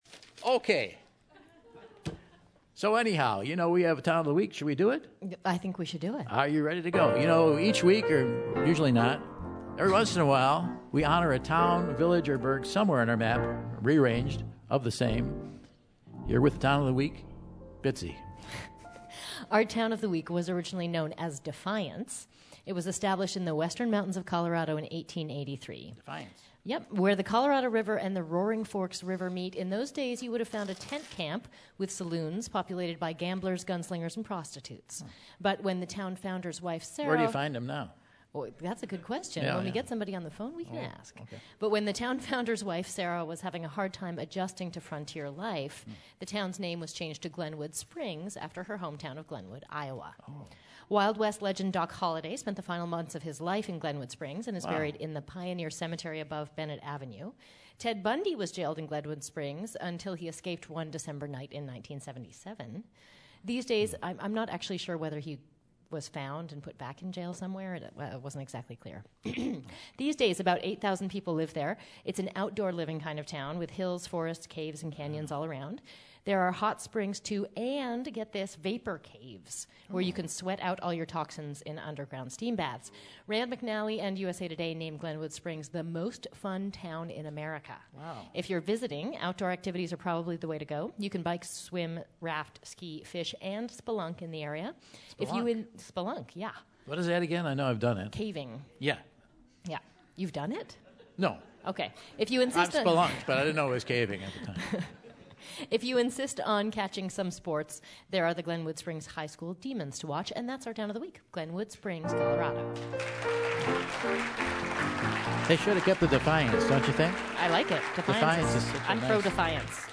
While Michael connects with a few people in the audience who share their memories of having been in the town.